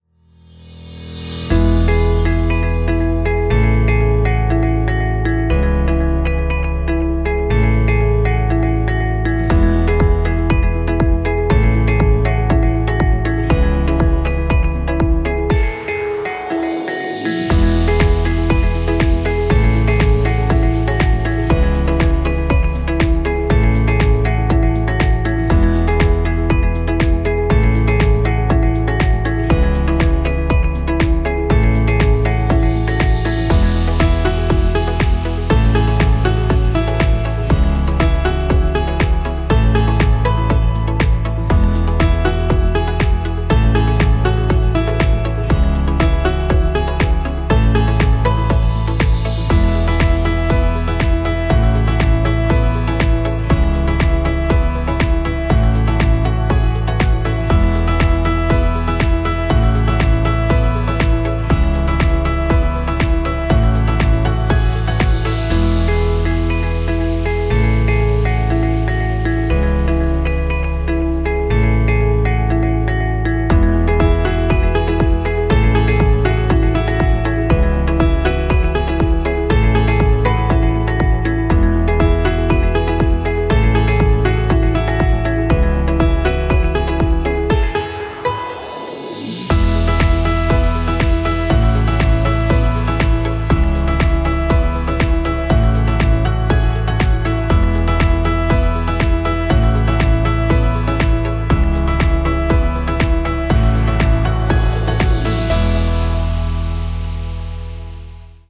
Genre: corporate, ambient.